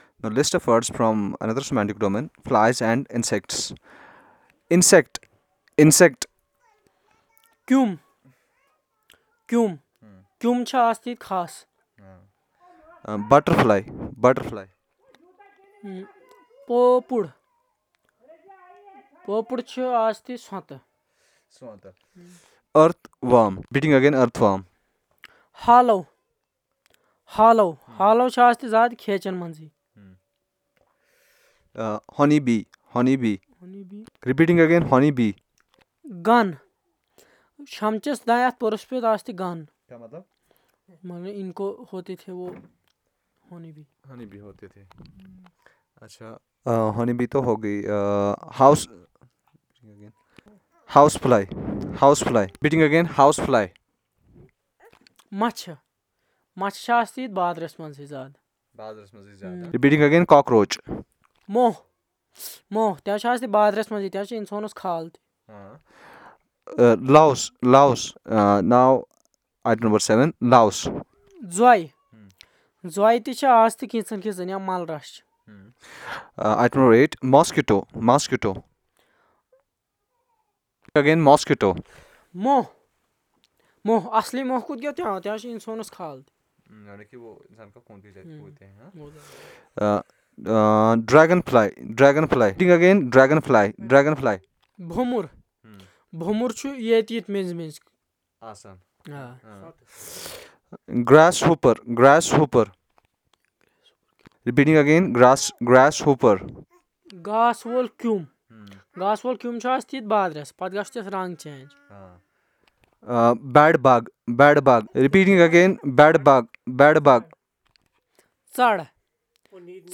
Elicitation of words about insects